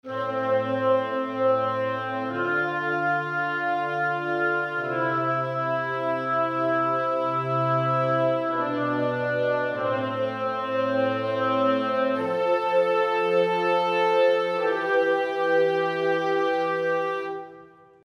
Will man anstelle des ganzen Ensembles nur ein legato gespieltes Duett aus (beispielsweise) Fagotten und Oboen realisieren, so lädt man einfach beide Instrumente, stellt die selbe Artikulation ein, legt sie im Panorama etwas auseinander und spielt beide zusammen über einen MIDI-Kanal: